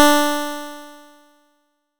nes_harp_D4.wav